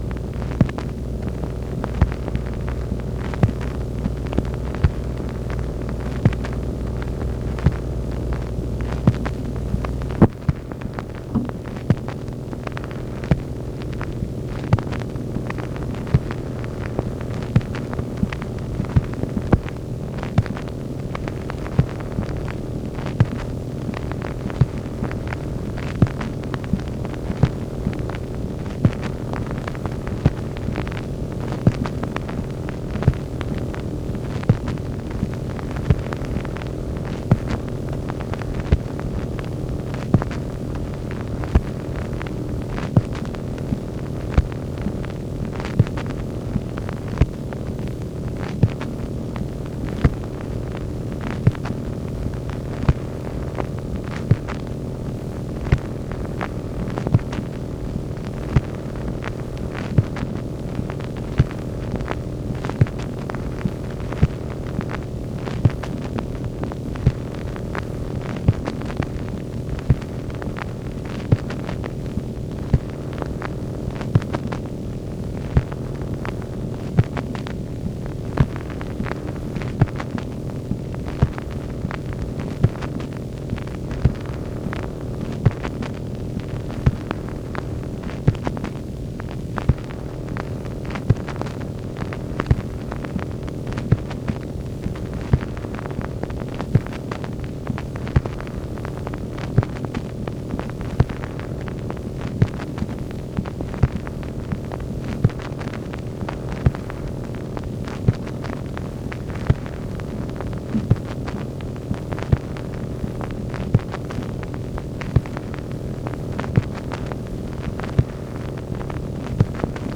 MACHINE NOISE, January 22, 1964
Secret White House Tapes | Lyndon B. Johnson Presidency